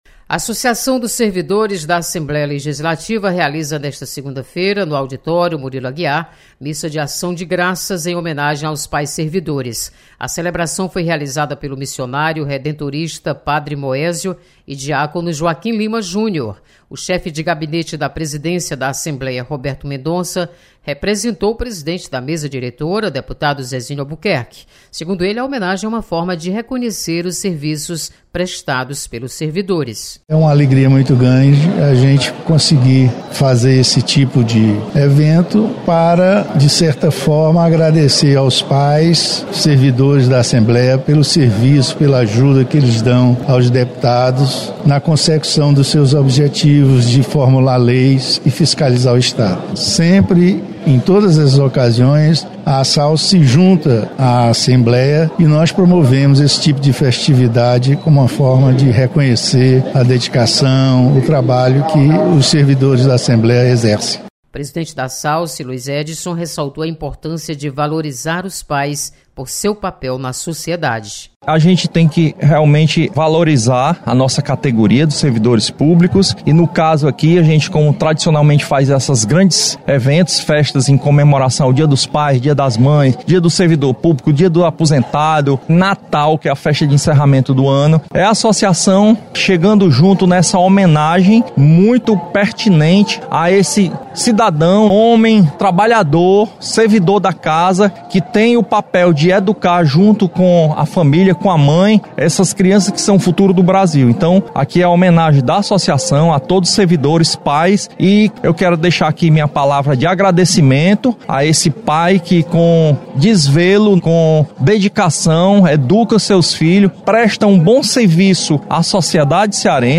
Você está aqui: Início Comunicação Rádio FM Assembleia Notícias Homenagem